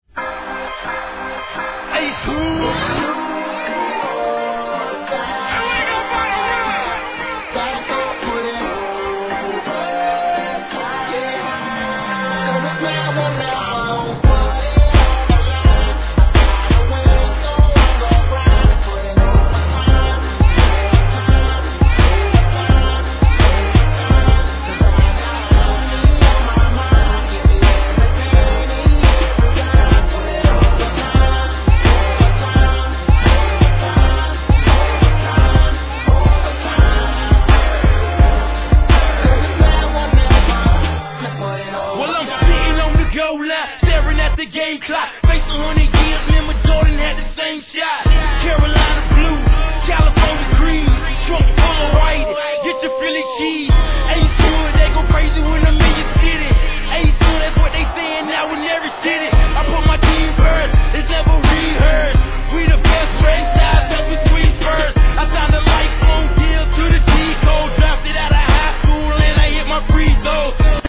HIP HOP/R&B
ストリングスが鳴り響くド派手なサウンドにハングリーなRAP